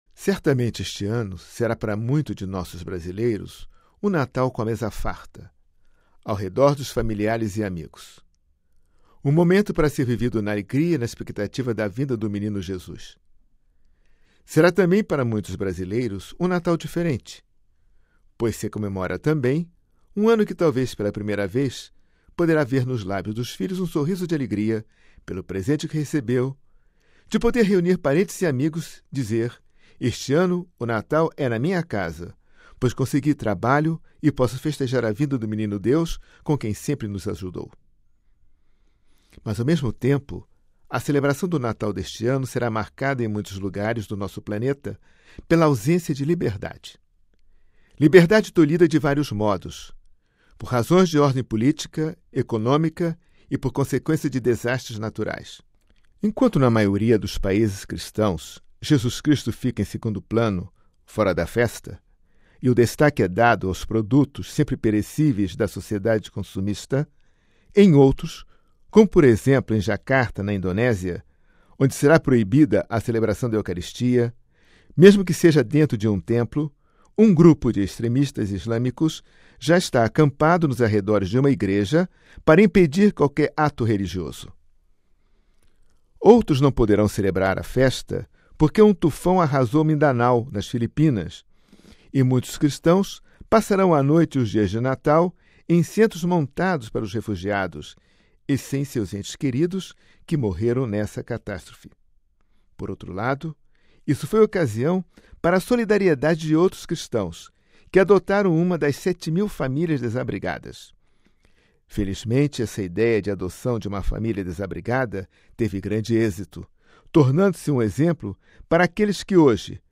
Editorial: um Natal diferente